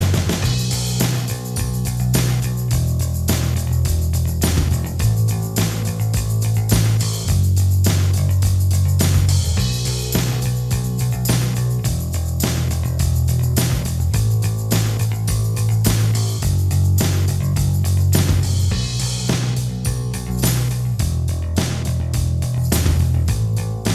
Minus Guitars Indie / Alternative 4:05 Buy £1.50